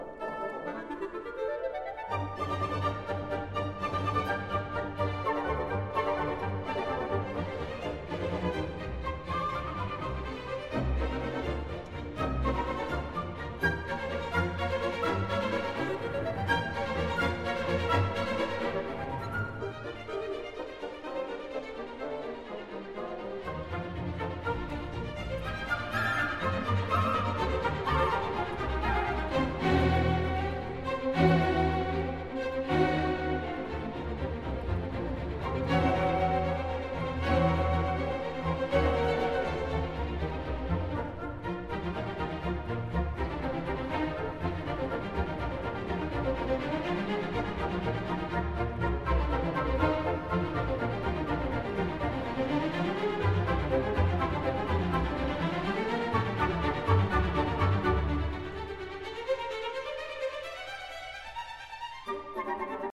Dotted Quarter= 82-84